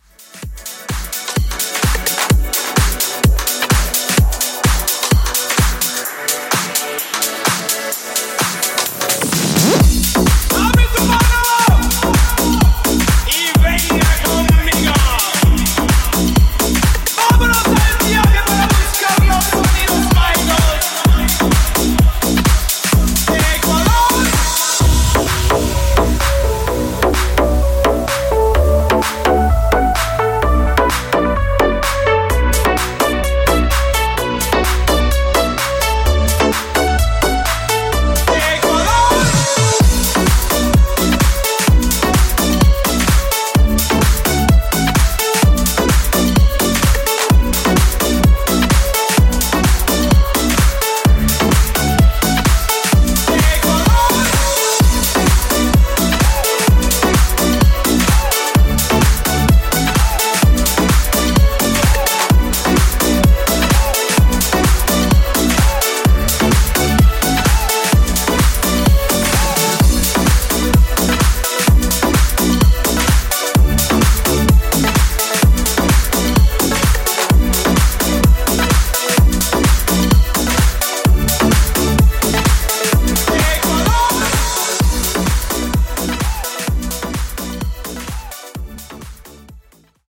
House Mix Repeat)Date Added